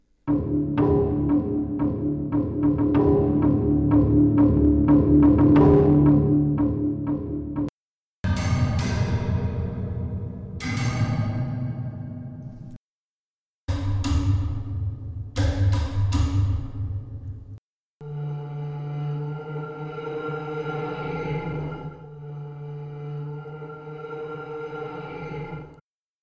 非常适合恐怖或任何音频制作，需要一些令人叹为观止的从未听过的声音。
我们录制了那些奇怪而有魅力的声音，同时用许多不同的木槌，棍棒，刷子，当然还有弓“演奏”了这些雕塑。
图书馆的内容是在索非亚会议工作室A录制的，有3个麦克风位置 - 关闭，Decca和大厅。